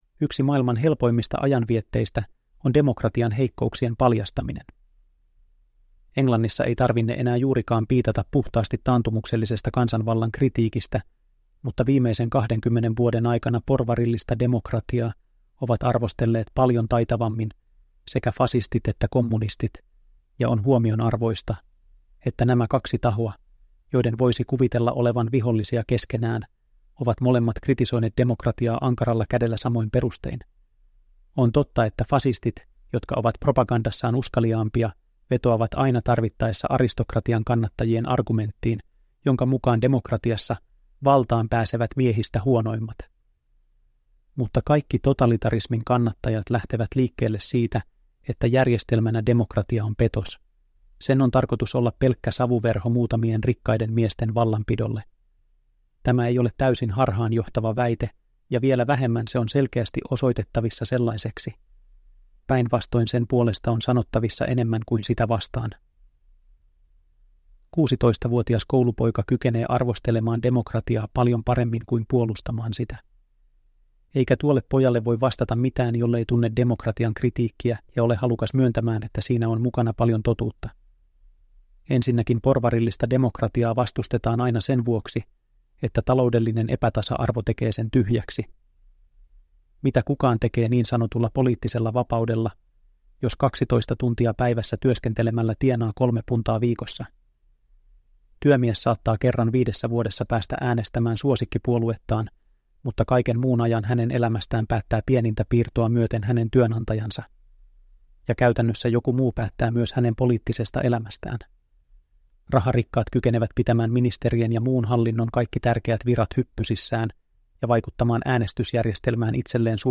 Fasismista ja demokratiasta (ljudbok) av George Orwell